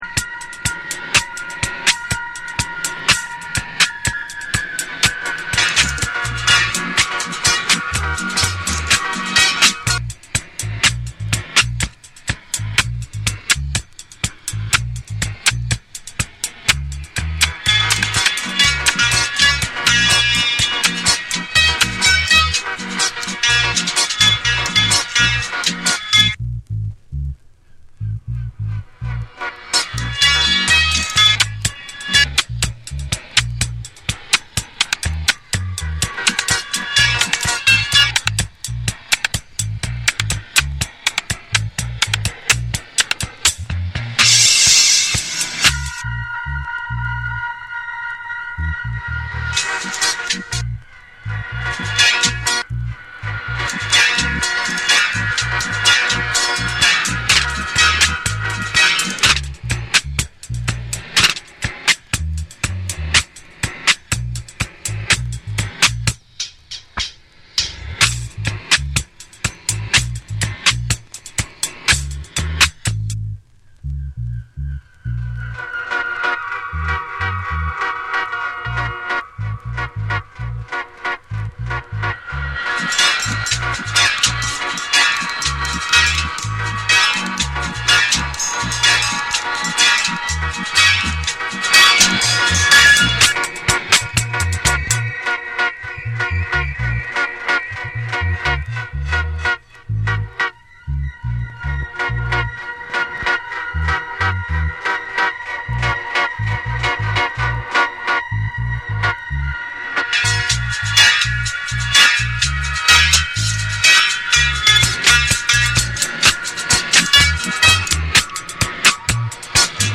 派手さはなくてもツボをおさえたダブワイズ
テープのリヴァースによるトビ音やコラージュを使った実験性等
盤面にキズ、ノイズ入る箇所あり。
REGGAE & DUB